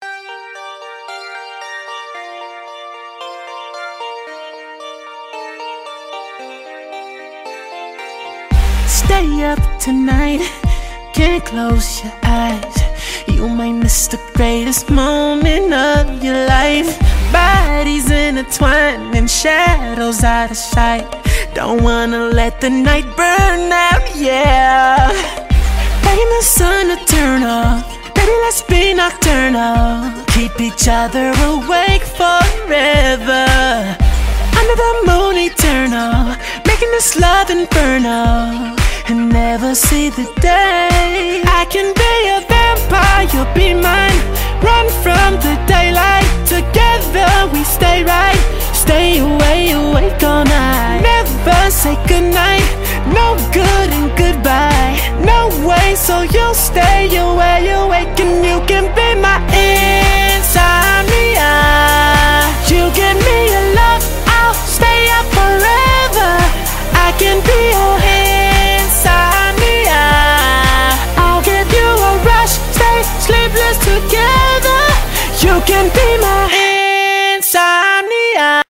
BPM113
autotune song